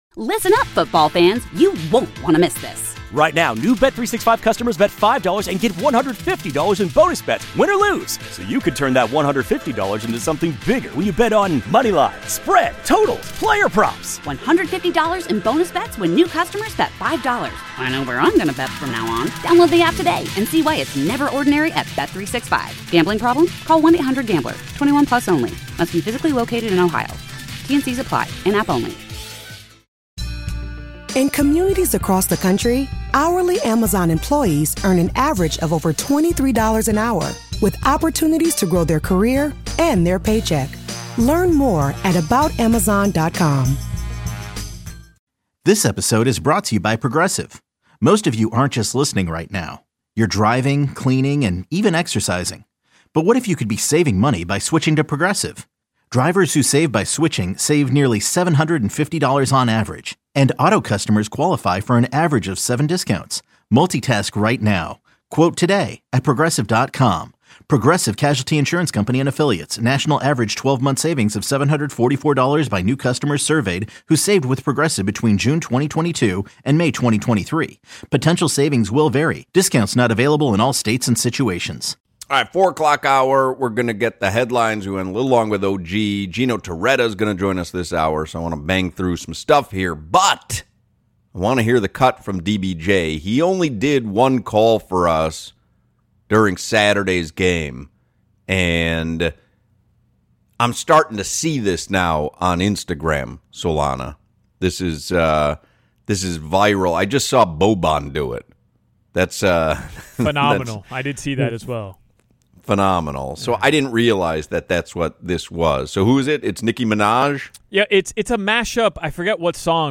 Has Carson Beck lived up to the hype? Gino Torretta joins the show for his thoughts.